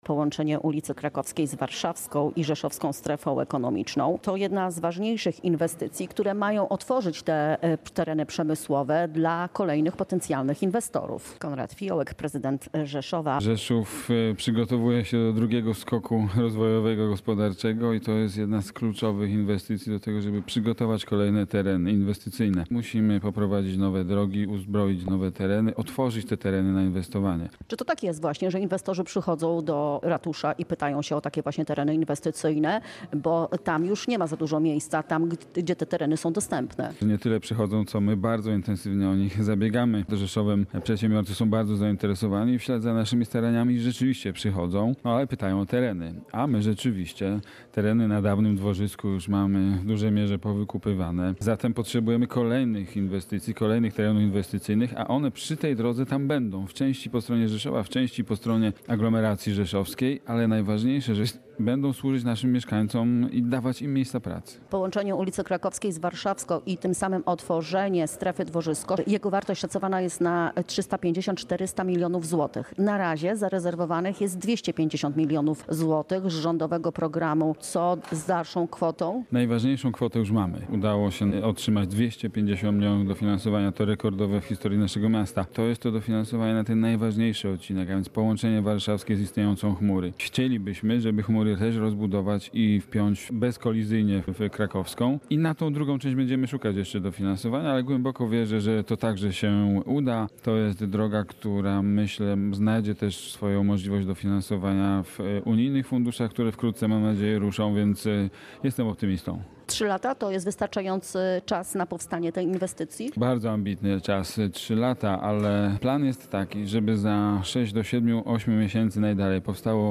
To jedna z najważniejszych inwestycji w Rzeszowie, bo terenami na Dworzysku zainteresowanych jest wielu inwestorów – podkreśla prezydent Rzeszowa Konrad Fijołek.